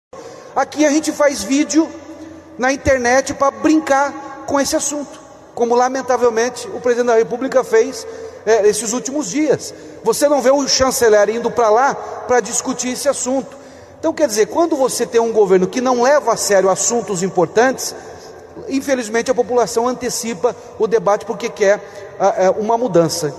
O governador do Paraná, Ratinho Junior (PSD), participou, neste sábado (26), de um painel durante o Expert XP, evento promovido pela XP Investimentos em São Paulo.